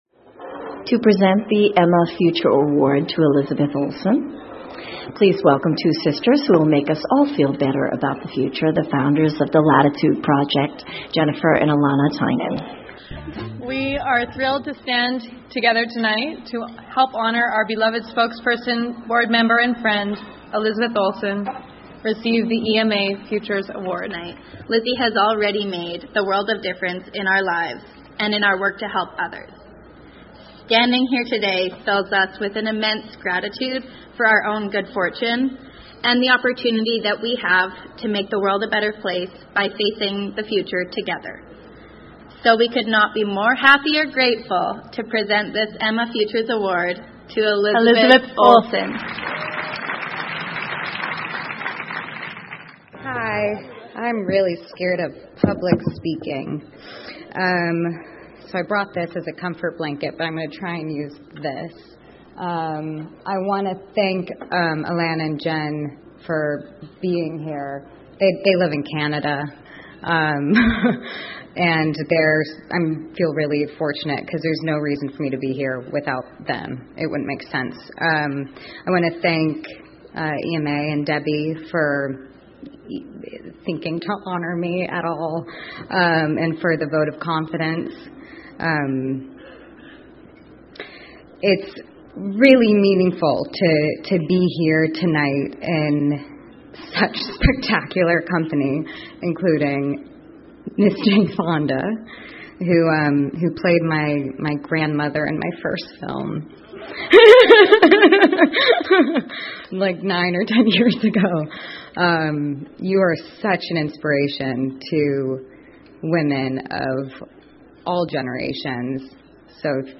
英文演讲录 伊丽莎白·奥尔森：获得艾玛未来奖的演讲(1) 听力文件下载—在线英语听力室